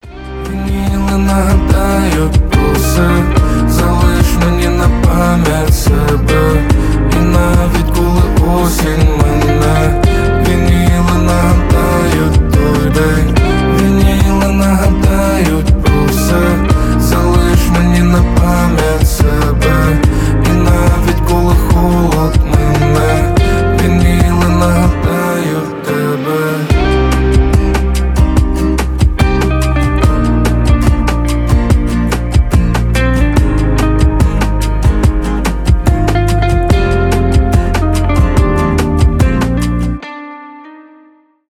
романтические
акустика , поп
гитара